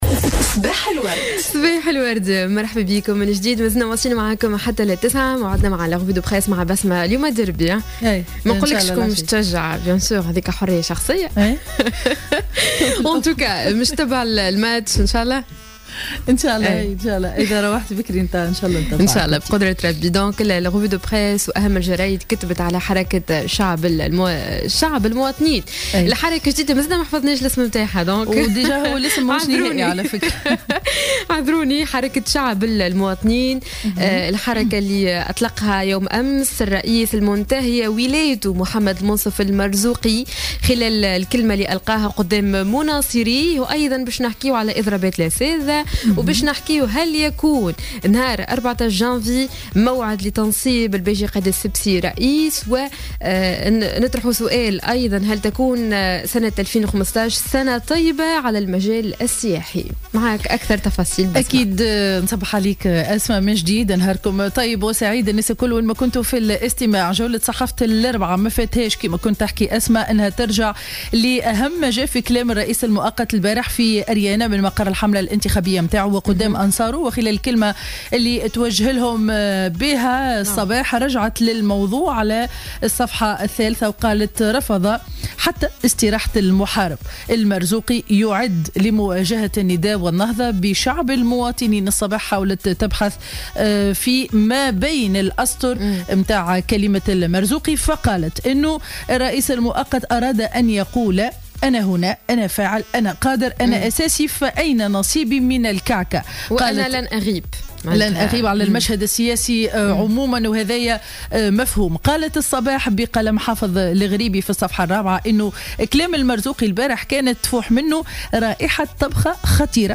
Revue de presse 24/12/2014 à 09:38